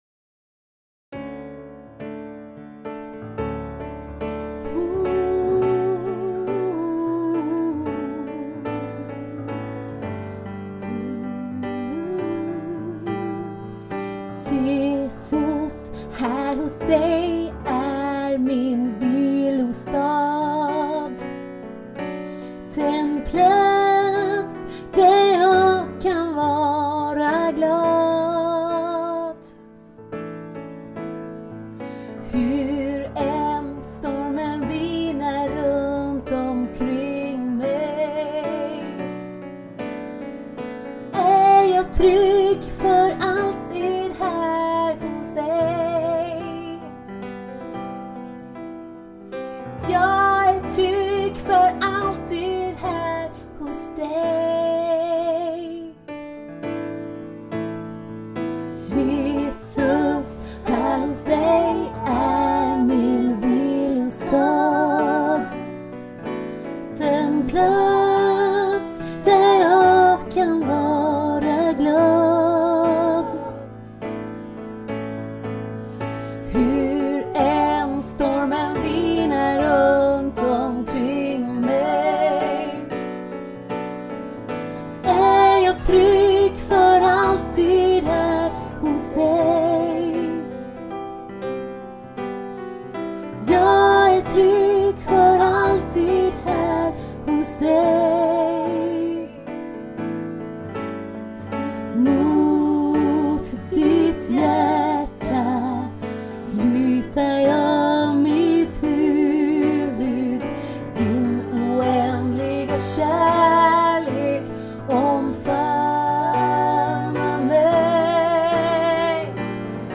Sånger för vila och stillhet och påfyllning i Guds närhet.